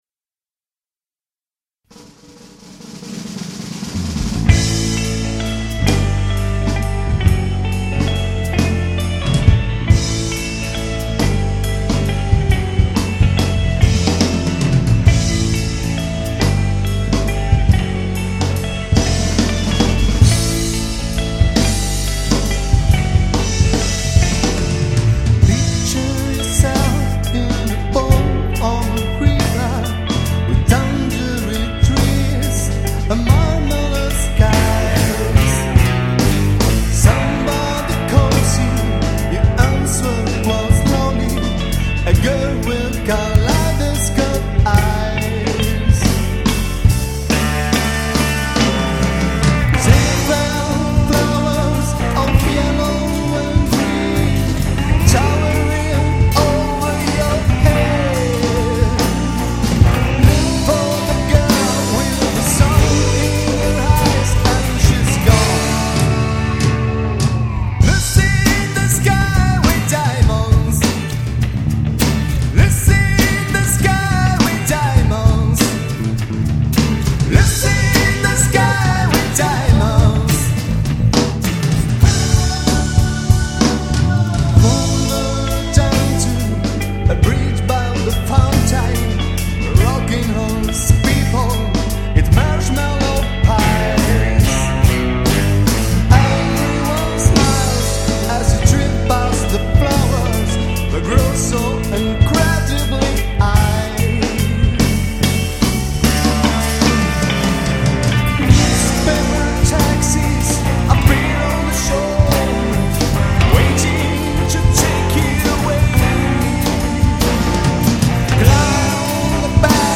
CHITARRA ELETTRICA